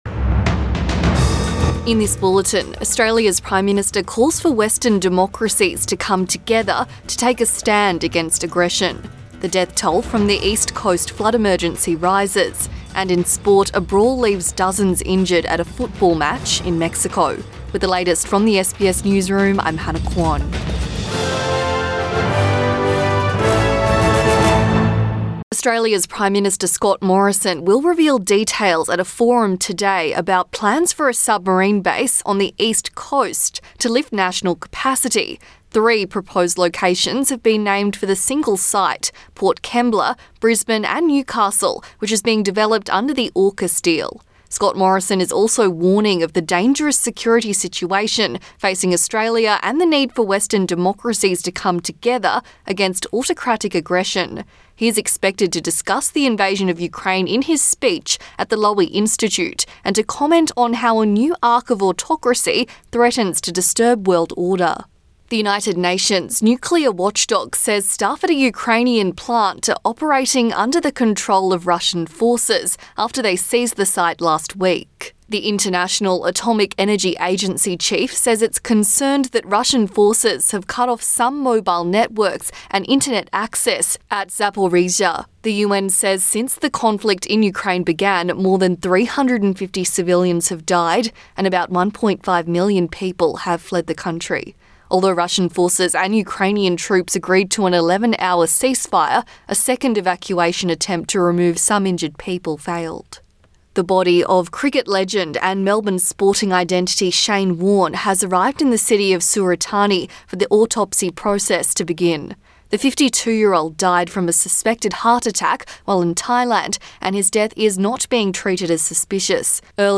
Midday bulletin 7 March 2022